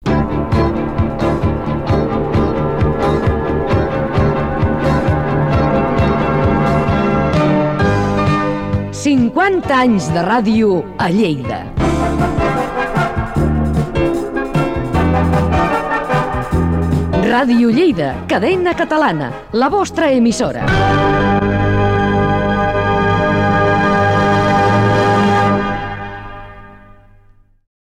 Identificació de l'emissora l'any dels seus 50 anys